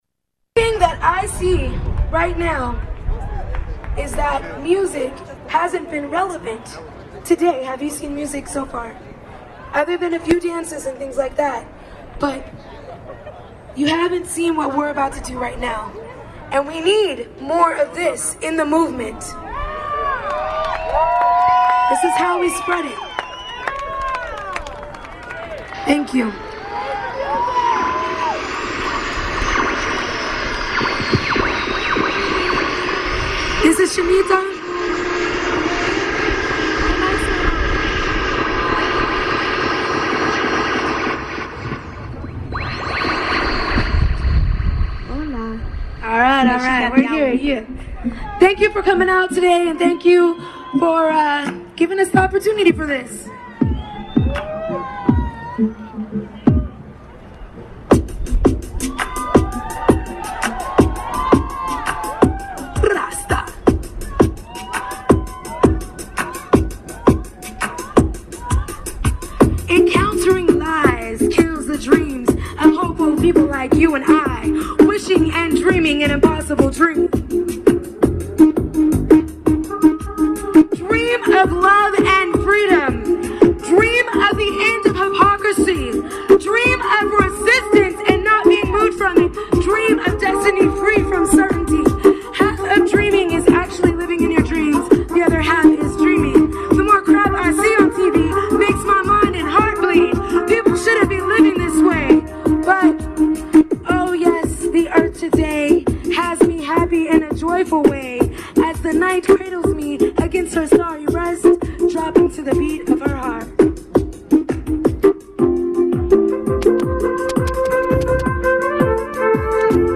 The file is a mp3 recording of their live performance from the main stage in the Civic Center in SF and lasts about 11 minutes.
Part of Brown Beret related group Dopa Doja performs at March 18 Antiwar Demo in SF.